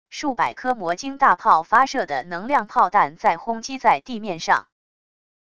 数百颗魔晶大炮发射的能量炮弹在轰击在地面上wav音频